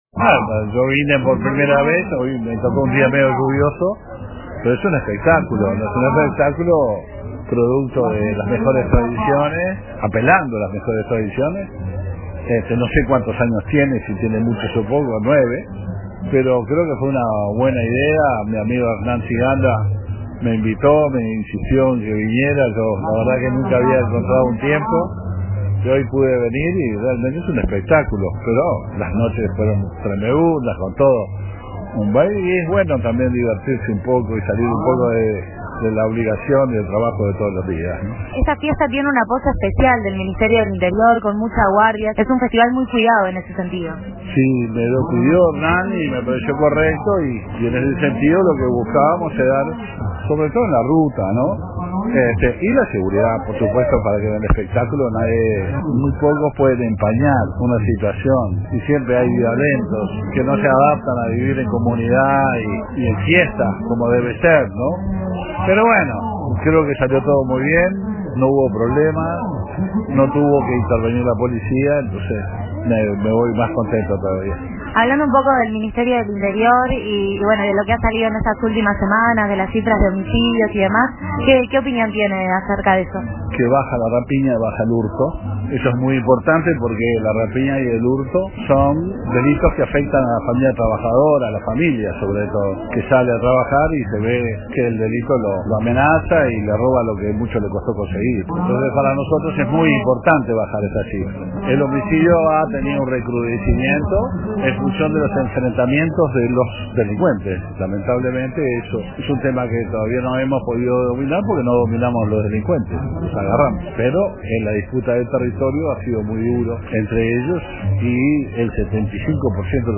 Entre los asistentes a la 9ª edición del Abrazo del Solís Grande, estuvo el Ministro del Interior, Luis Alberto Heber.